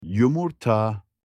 در زبان ترکی استانبولی، معادل کلمه تخم مرغ ، “Yumurta” (یومورتا) است.
Yumurta.mp3